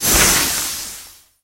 gale_atk_01.ogg